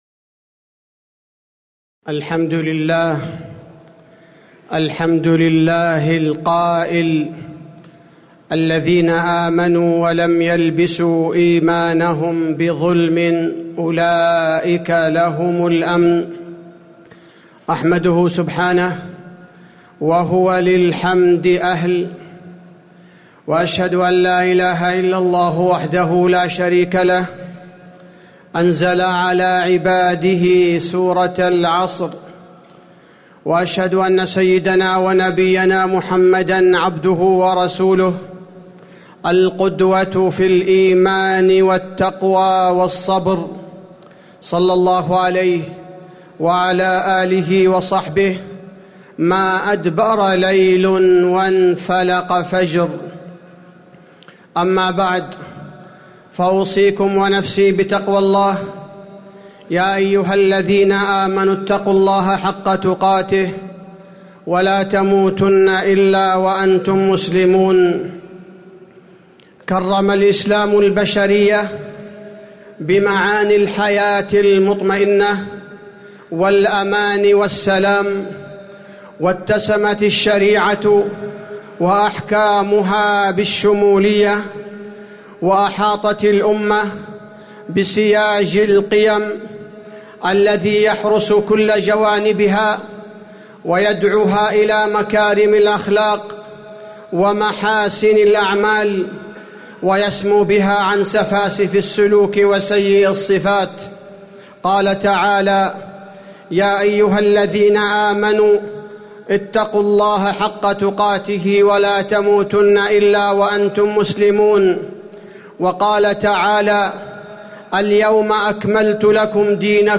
تاريخ النشر ١٦ محرم ١٤٣٩ هـ المكان: المسجد النبوي الشيخ: فضيلة الشيخ عبدالباري الثبيتي فضيلة الشيخ عبدالباري الثبيتي التحرش وكيفية ردعه